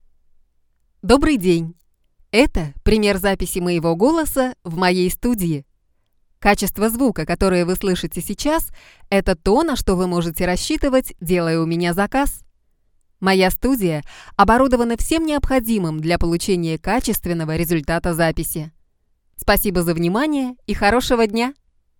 Kein Dialekt
Sprechprobe: Industrie (Muttersprache):
Can do Child, Young Female, Middle Age Female, Senior Female timbre of voice.